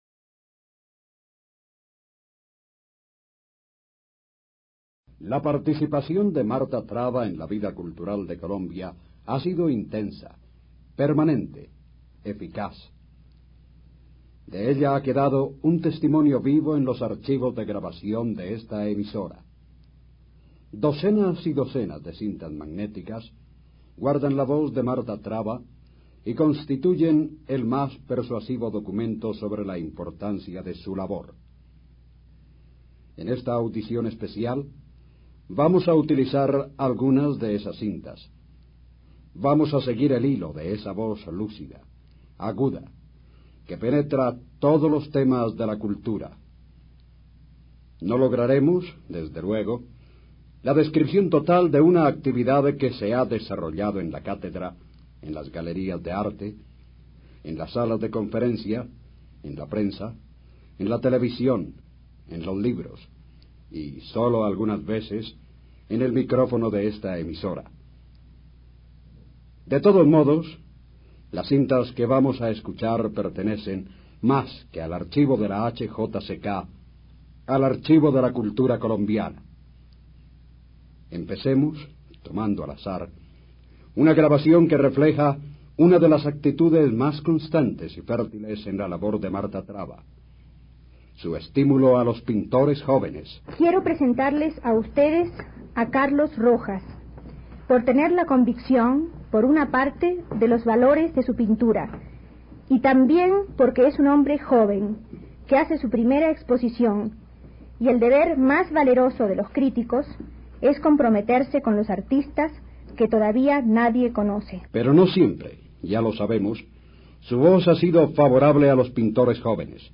Así eran los programas de radio de Marta Traba